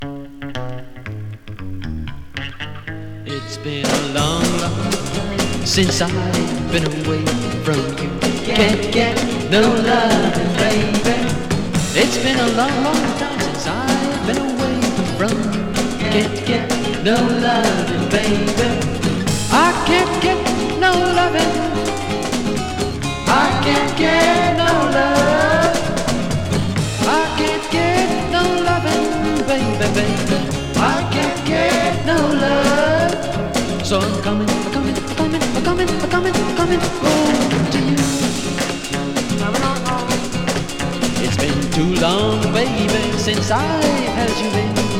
Calypso, Pop, World　Belgium　12inchレコード　33rpm　Stereo